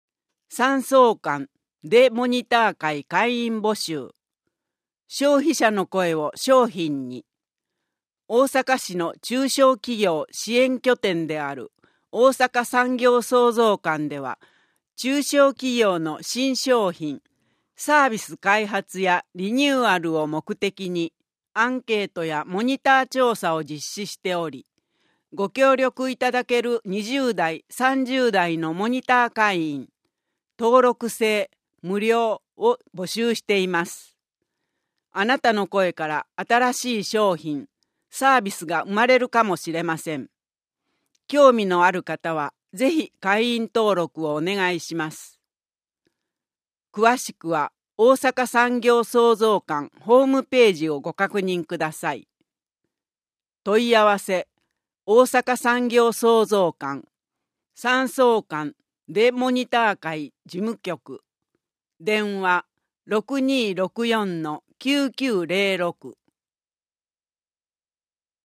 音訳版「よどマガ！」（令和5年12月号）